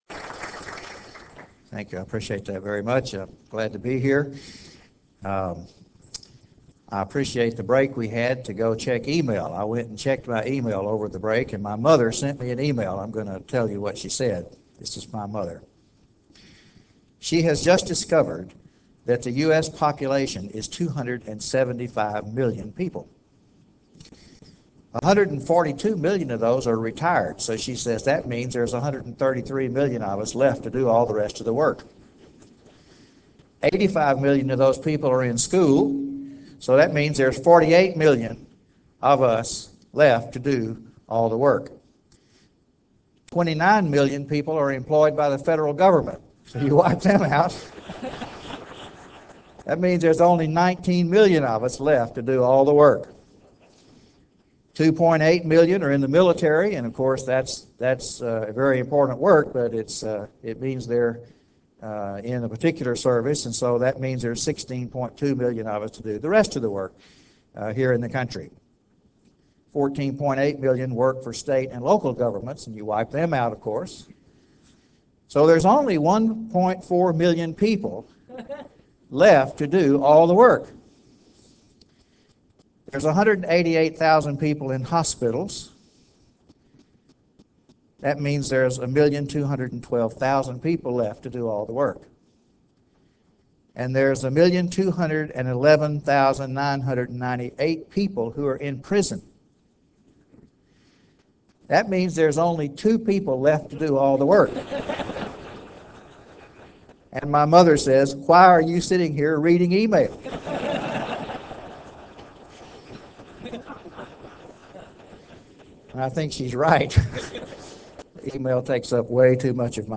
Baptist Identity Conference